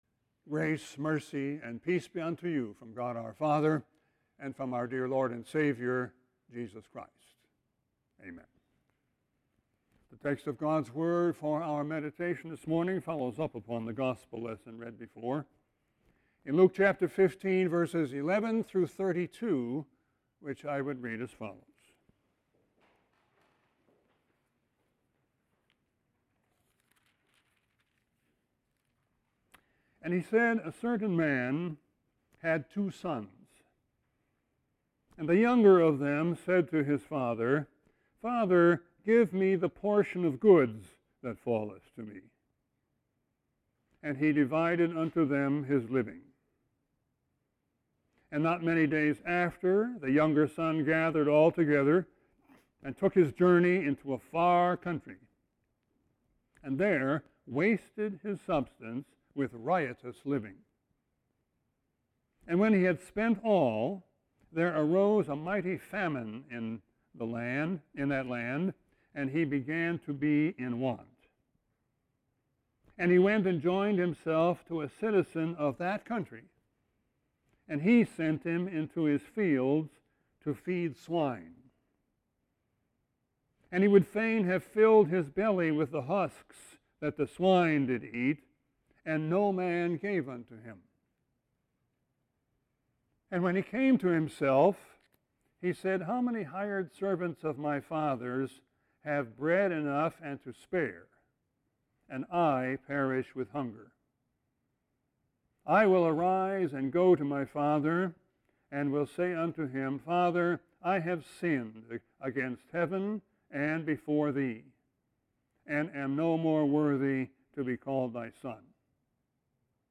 Sermon 6-20-21.mp3